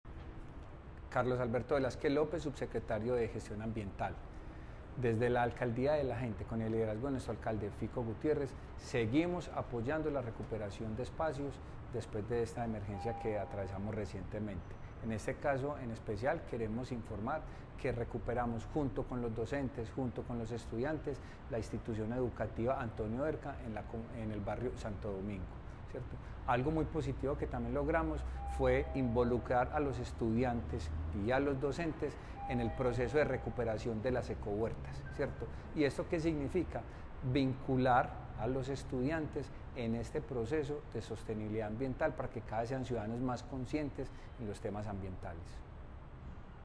Declaraciones del subsecretario de Gestión Ambiental, Carlos Velásquez.
Declaraciones-del-subsecretario-de-Gestion-Ambiental-Carlos-Velasquez.-Santo-Domingo-Savio.mp3